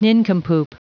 Prononciation du mot nincompoop en anglais (fichier audio)
nincompoop.wav